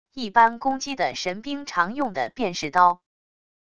一般攻击的神兵常用的便是刀wav音频生成系统WAV Audio Player